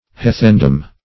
Search Result for " heathendom" : The Collaborative International Dictionary of English v.0.48: Heathendom \Hea"then*dom\ (-d[u^]m), n. [AS. h[=ae][eth]end[=o]m.] 1.